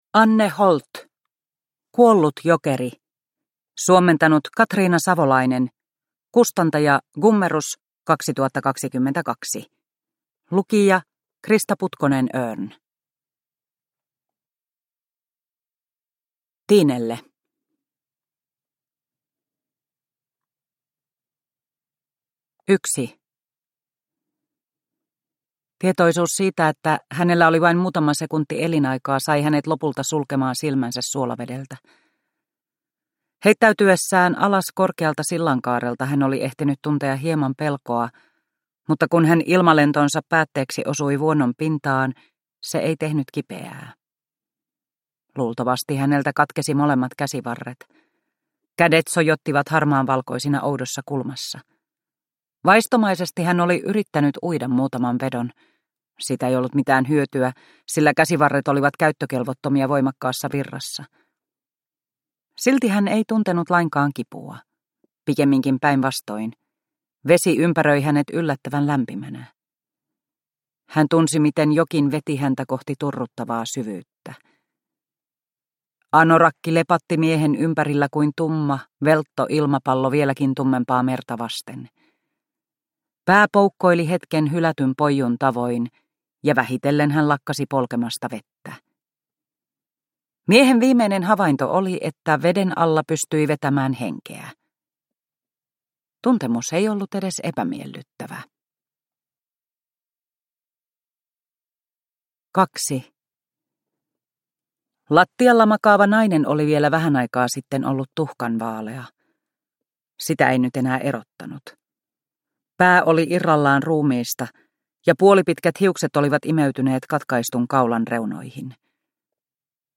Kuollut jokeri – Ljudbok – Laddas ner